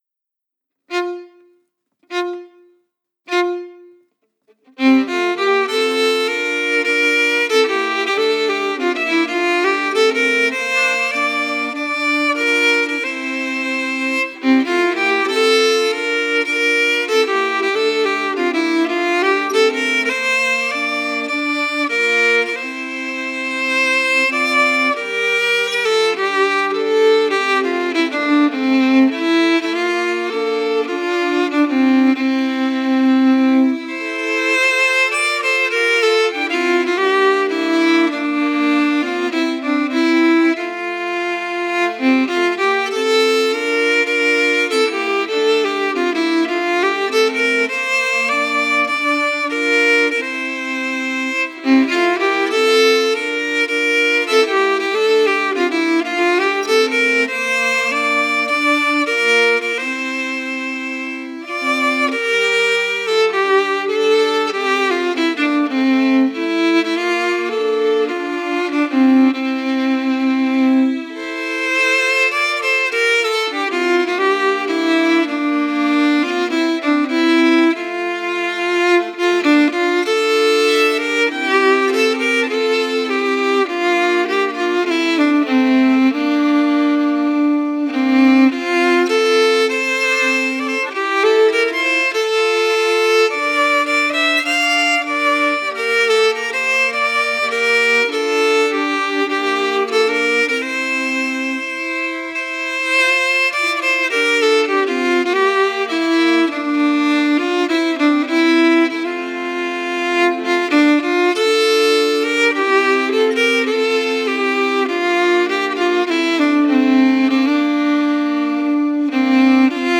Key: F*
Form: Slow March
Melody emphasis
Genre/Style: Swedish slow march